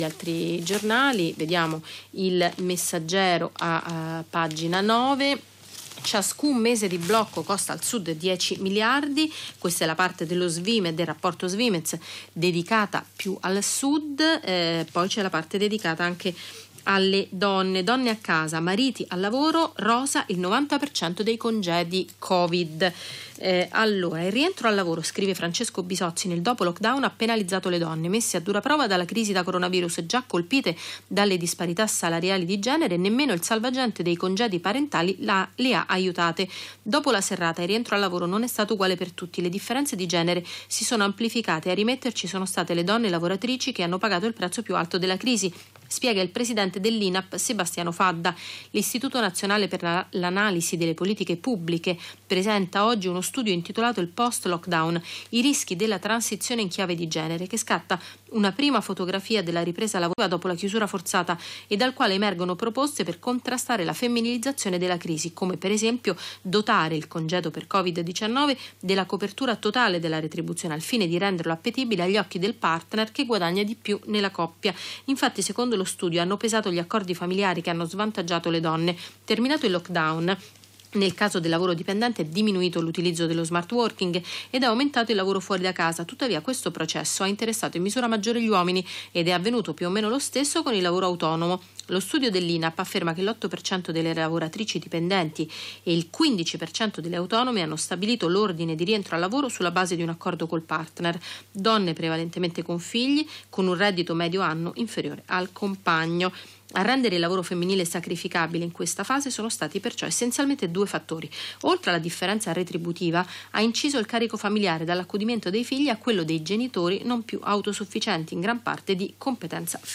Rassegna Stampa: dal quotidiano "il Messaggero". Donne a casa, mariti al lavoro, rosa il 90% dei congedi Covid. a rimetterci sono state le donne lavoratrici che hanno pagato il prezzo più alto della crisi spiega il presidente dell'INAPP Sebastiano Fadda che presenta oggi uno studio intitolato "Post Lockdown"